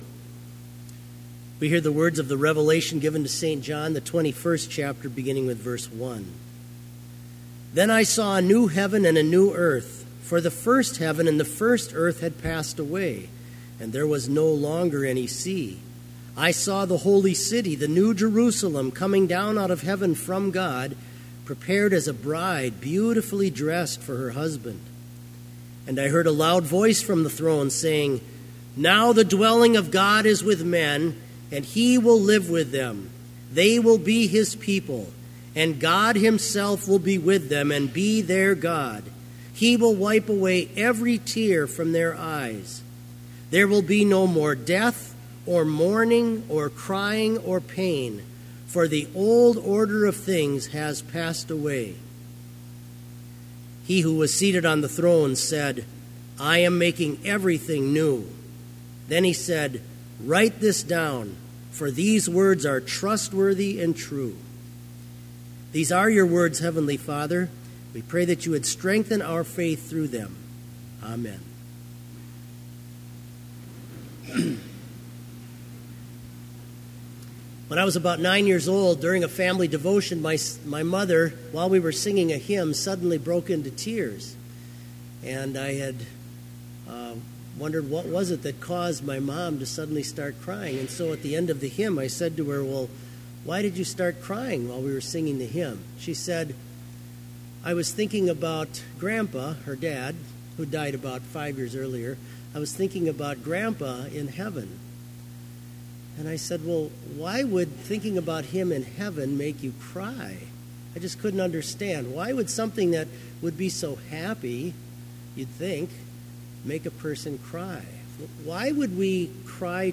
Complete service audio for Chapel - November 29, 2017
Prelude Hymn 540, Ye Watchers and Ye Holy Ones